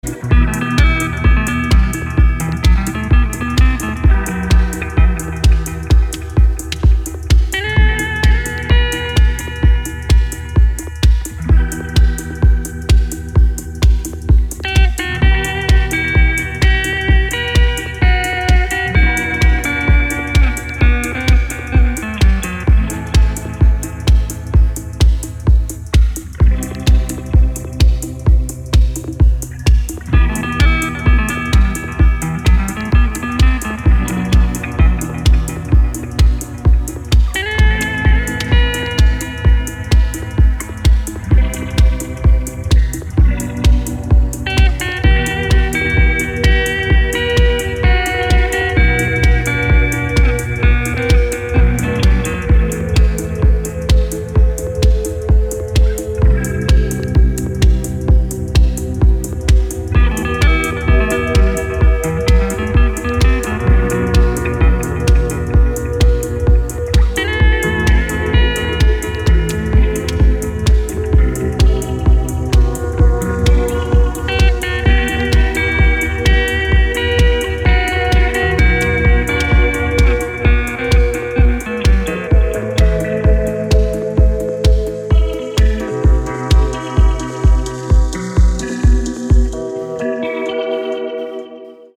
красивые
deep house
dance
Electronic
спокойные
без слов
электрогитара
electro house